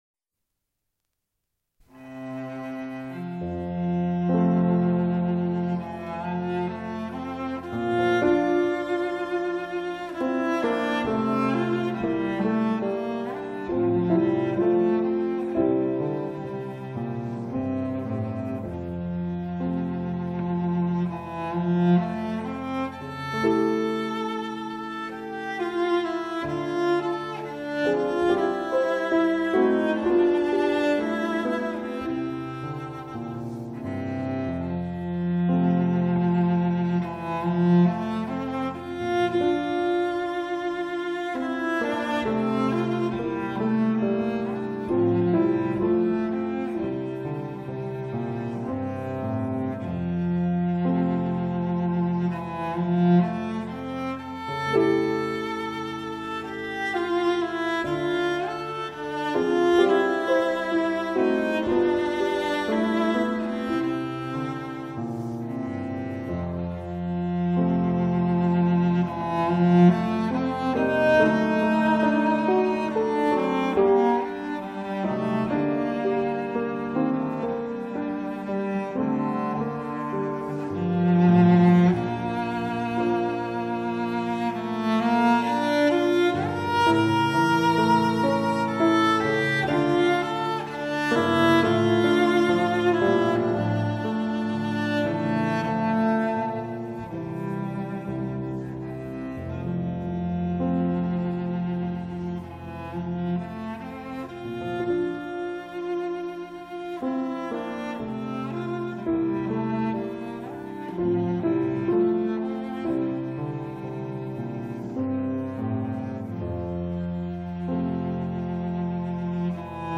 R. Schumann - Kinderszenen för piano op. 15 nº7 - Träumerei